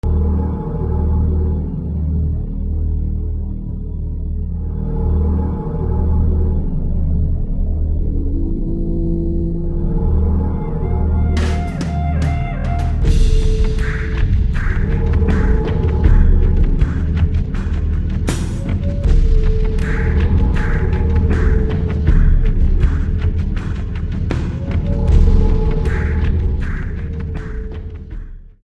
Compelling compositions, haunting and
mysterious